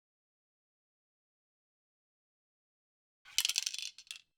spray_additionals_002.wav